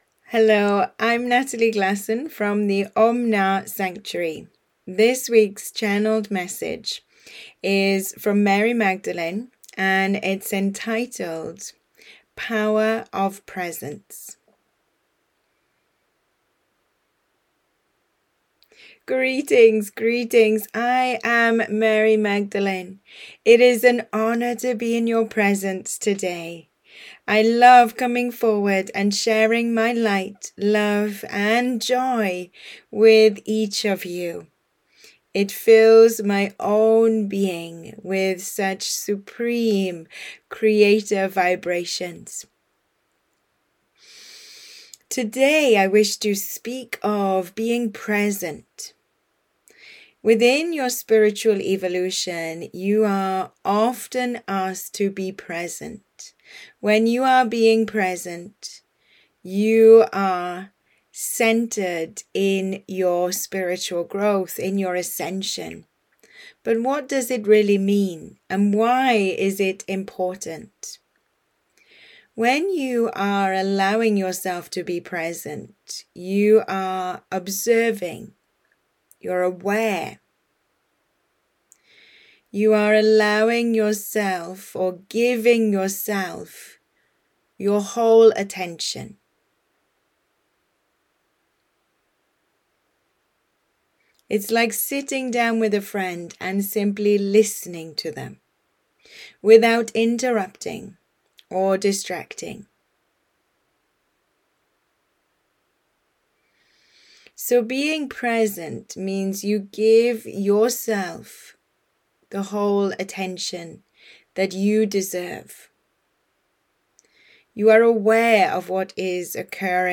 Channeled Message